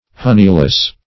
Honeyless \Hon"ey*less\, a. Destitute of honey.